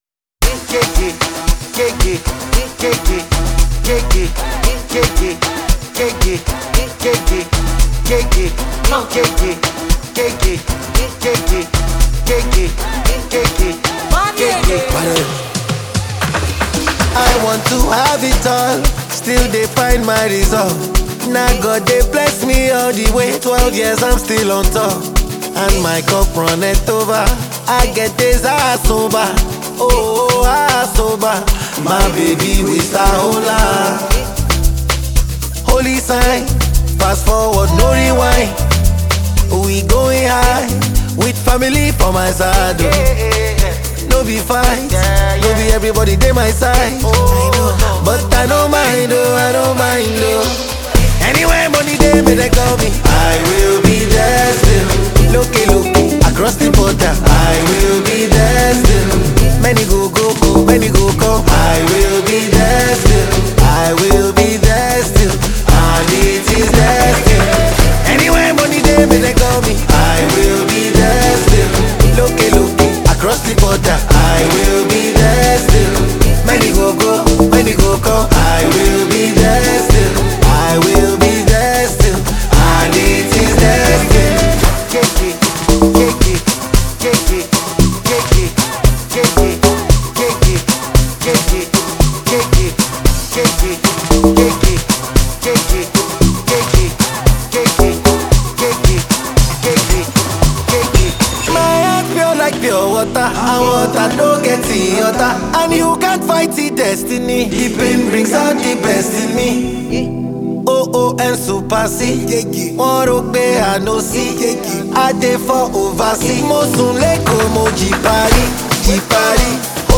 with its unforgettable melody and irresistible vibe.